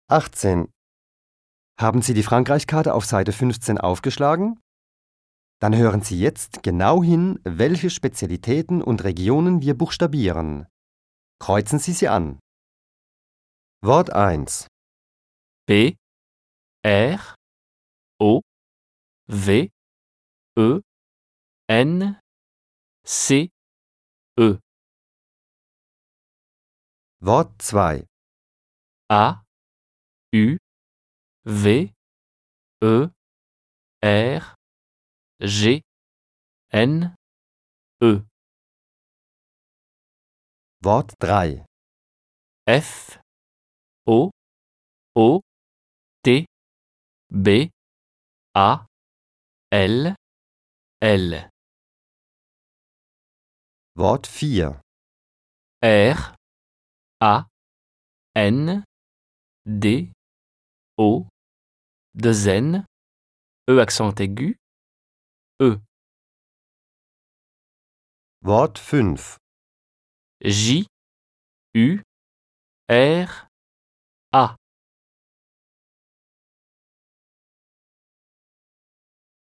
Audio ist Trumpf: Das Set enthält 8 Audio-CDs, die den Kurs mit den Lektionstexten, alltagsnahen Hörspielen und Übungen begleiten